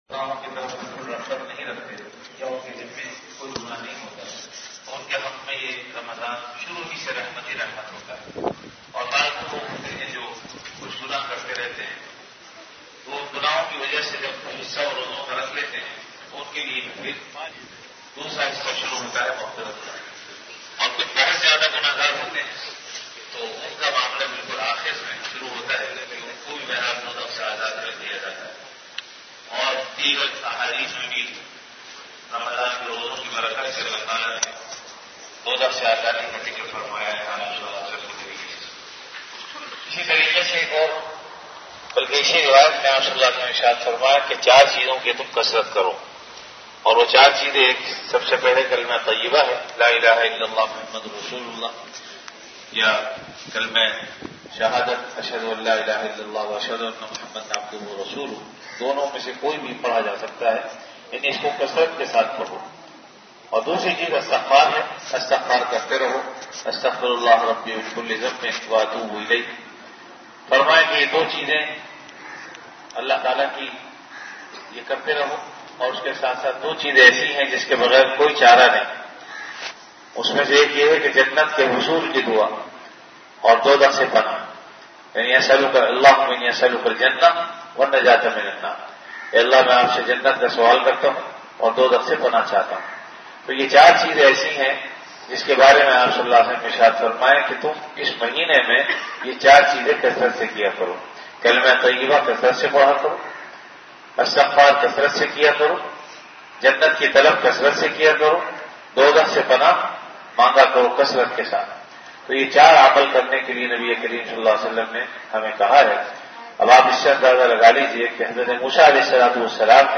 CategoryRamadan - Dars-e-Hadees
VenueJamia Masjid Bait-ul-Mukkaram, Karachi
Event / TimeAfter Fajr Prayer